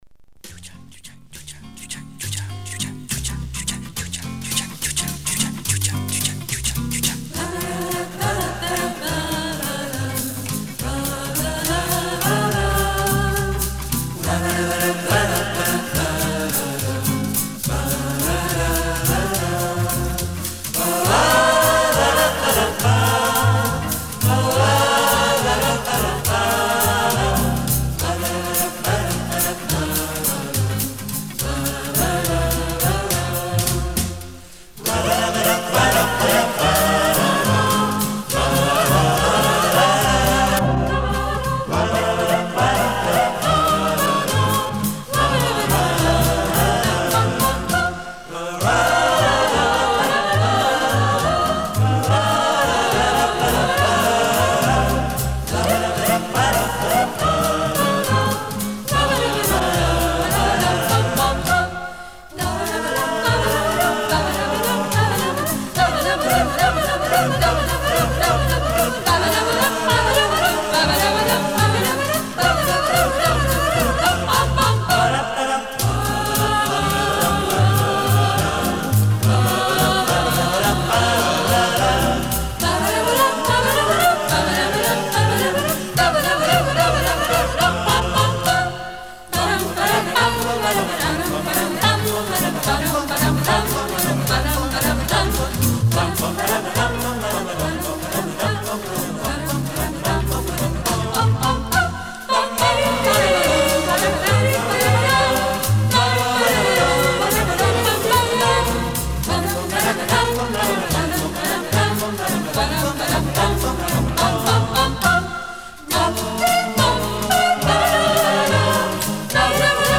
Как активно они здесь поют. Прямо свингл сингерс.